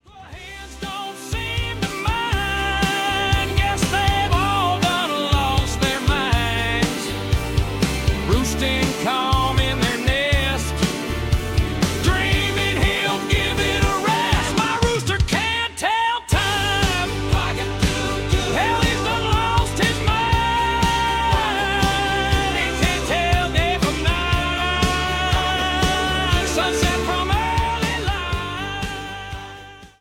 NEW REMASTERED RECORDING!
Rough and rowdy one minute, tender the next.